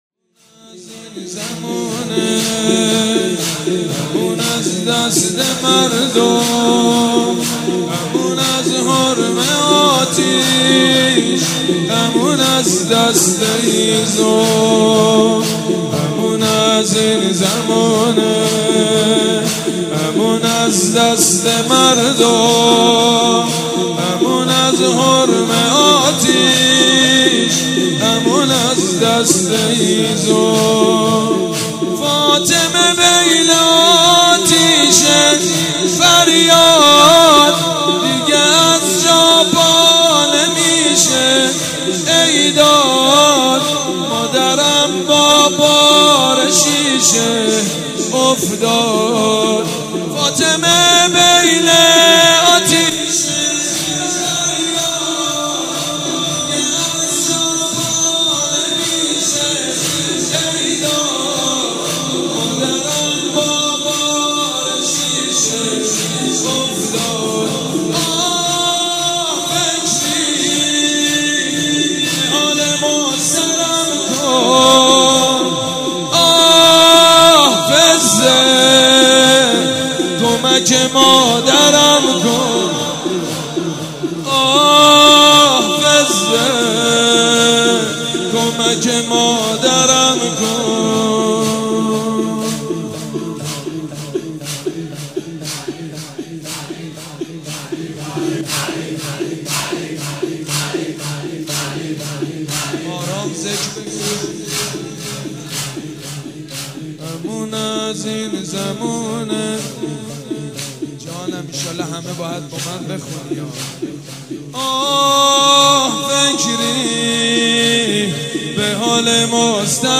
مناسبت : شهادت حضرت فاطمه زهرا سلام‌الله‌علیها
مداح : سیدمجید بنی‌فاطمه قالب : زمینه